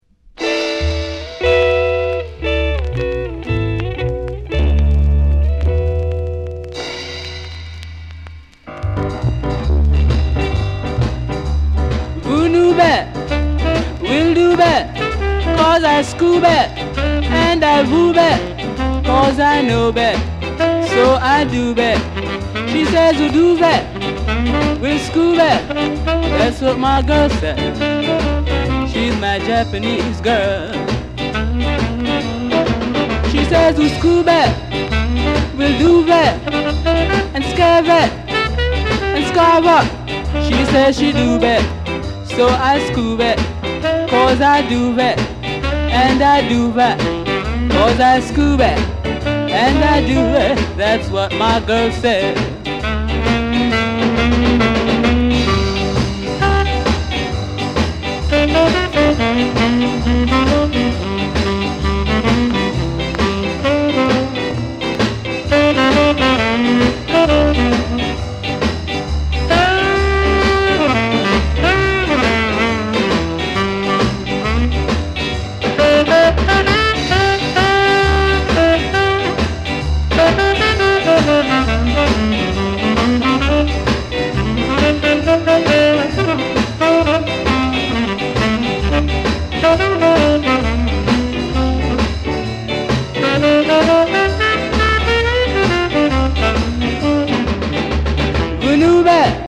スカ・レゲエ
60年代中期のヴィンテージ感がたまらんです。